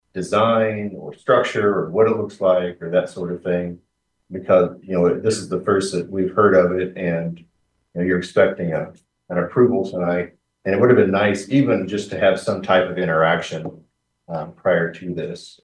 Before the vote, City Councilman Tye Parson expressed his disasssifaction that the council had no input on the bridge.
tye talking about pedestrian bridge.mp3